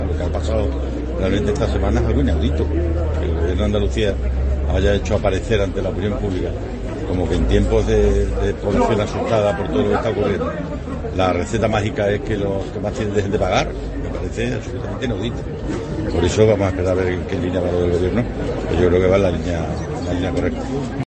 "Por eso, vamos a esperar a ver en qué línea va lo del Gobierno, que yo creo que va en la línea correcta", ha incidido a preguntas de los medios durante su asistencia este viernes a varios actos de tipo cultural en Trujillo (Cáceres).